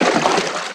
High Quality Footsteps
STEPS Water, Stride 06.wav